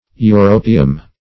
europium - definition of europium - synonyms, pronunciation, spelling from Free Dictionary
Europium \Eu*ro"pi*um\, n. [NL.; Europe + -ium, as in